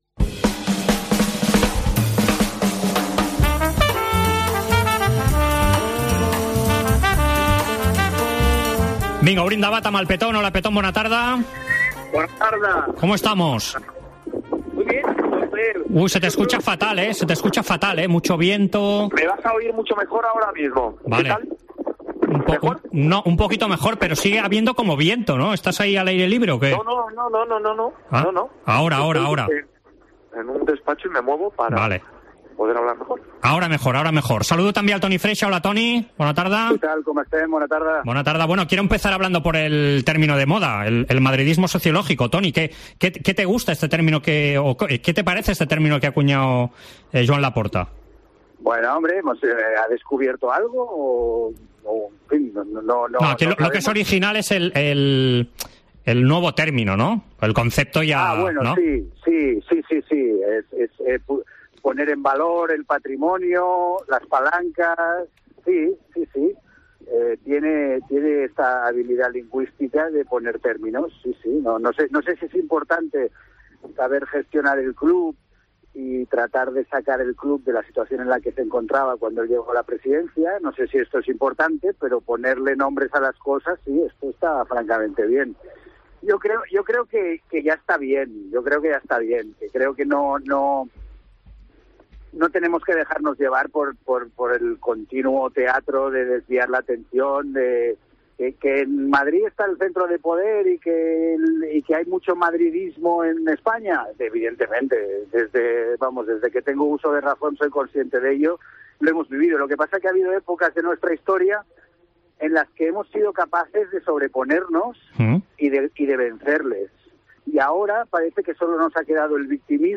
AUDIO: Els dos col·laboradors de la Cadena COPE repassen l'actualitat esportiva d'aquesta setmana.